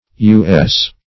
U.S. - definition of U.S. - synonyms, pronunciation, spelling from Free Dictionary